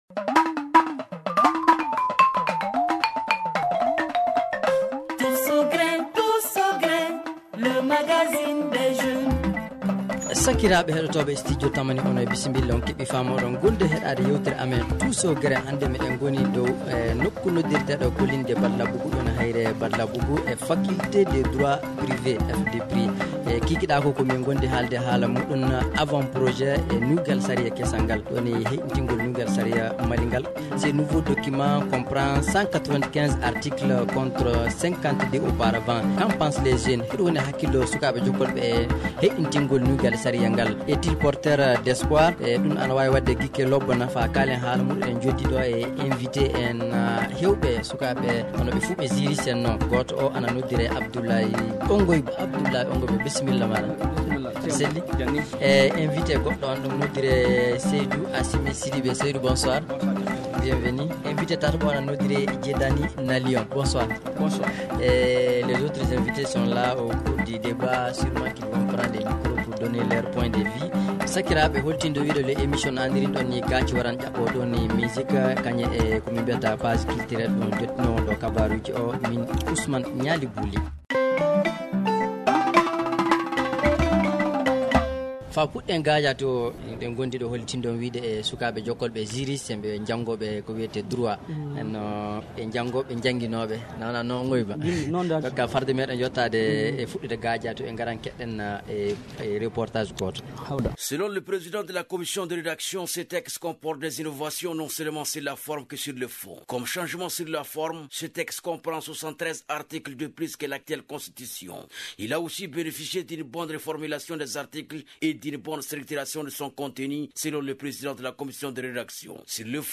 Le Tous au Grin de cette semaine pose le débat avec nos invités sont :